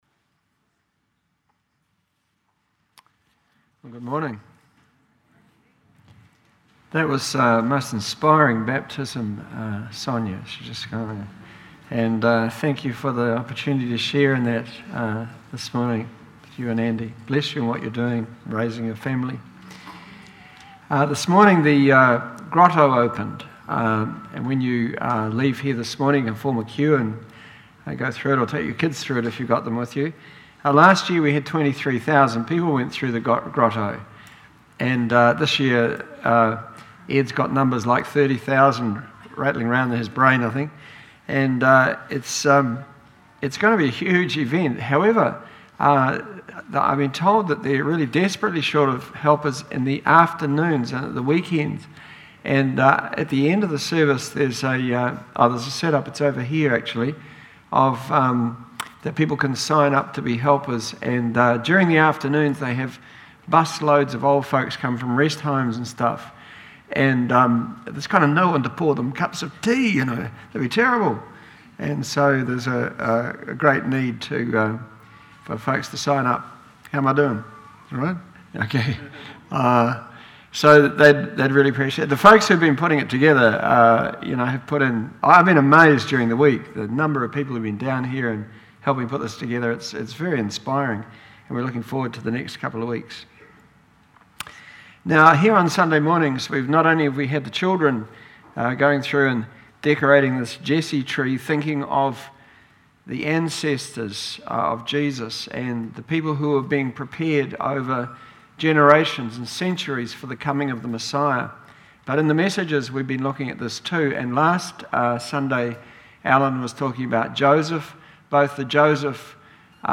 A Promise Comes True 11am service